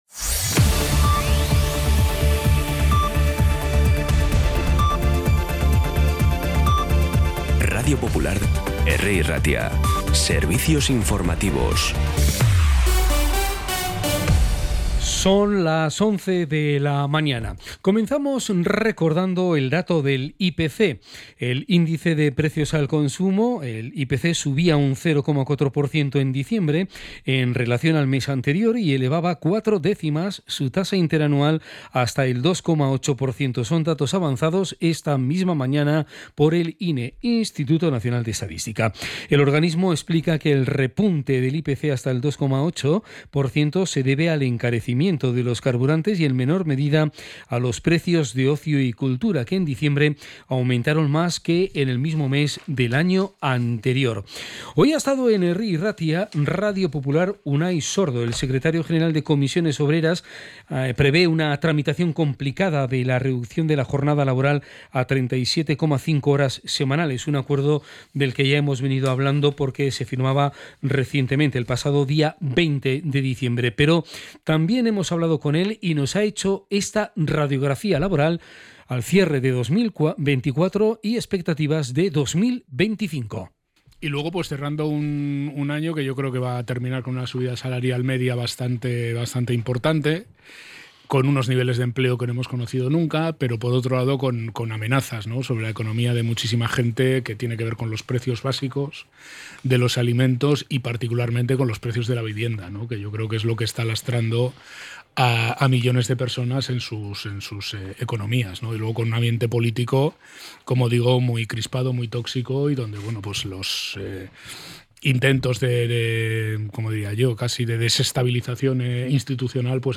Podcast Informativos
Los titulares actualizados con las voces del día.